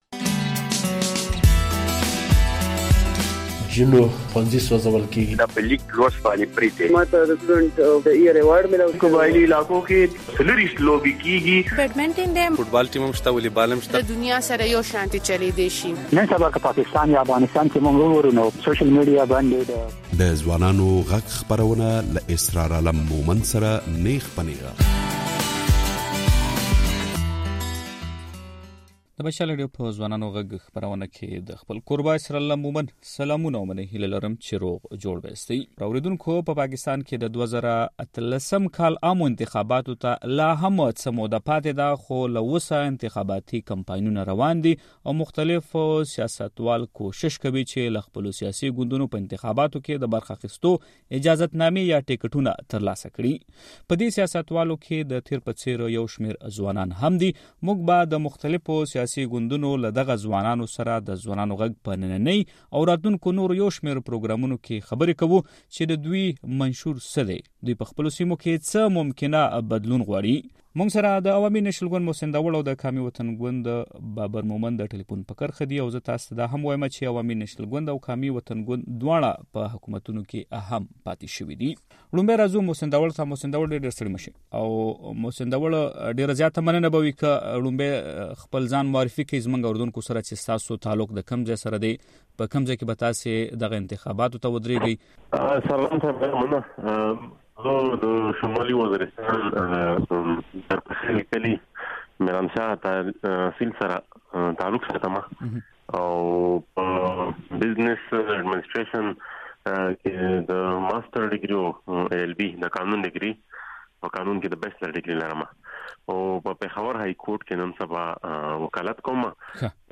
اوريدونکو د پاکستان د ۲۰۱۸ م کال په عامو انتخاباتو کې د برخه اخستو لپاره يو شمير ځوانان په دې لټه کې دي چې له خپلو اړونده سياسي ګوندونو ټکټونه ترلاسه کړي. د نن په ځوانانو غږ پروګرام کې له هم داسې ځوانو سياستوالو سره خبرې کوو، کوم چې کېدای شي په راتلونکو عامو انتخاباتو کې برخه واخلي او ممکن ده چې د واک او اختيار خاوندان جوړ شي. له دوي سره پر دې خبرې کوو چې د خپلو خلکو د ژوند ښه کولو لپاره څه پلانونه يا منصوبې لري؟